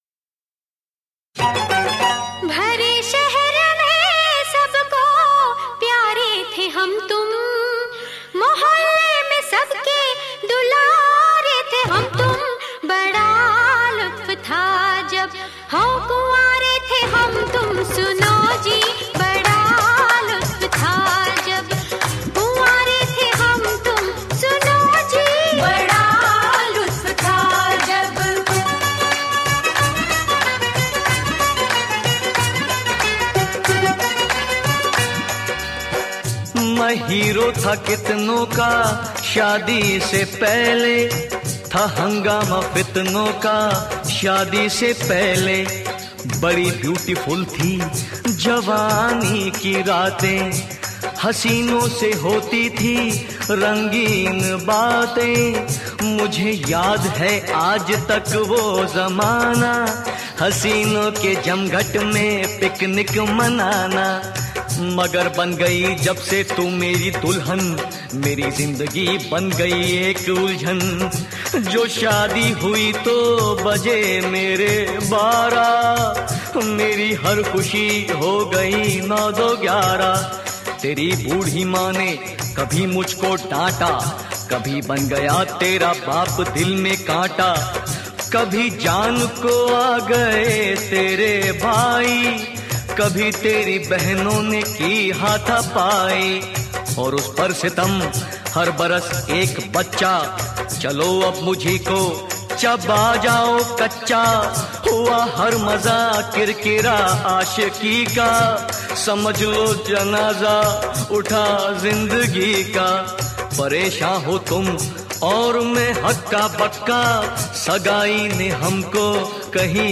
Urdu Qawwali MP3